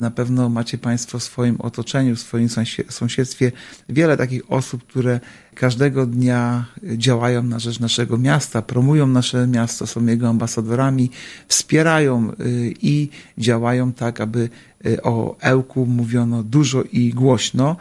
Artur Urbański – zastępca prezydenta Ełku.